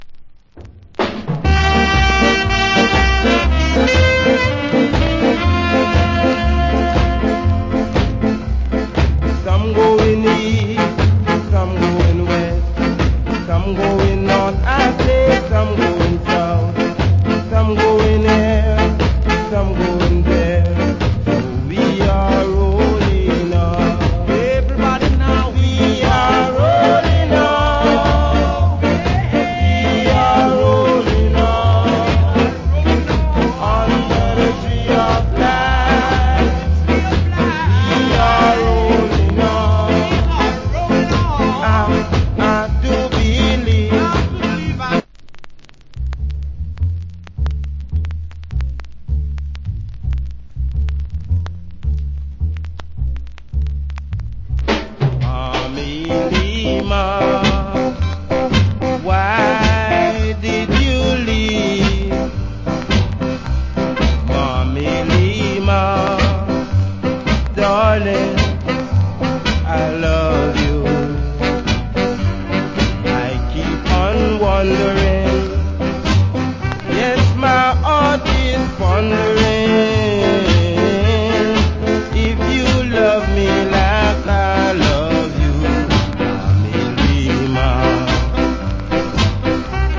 Great Ska Vocal.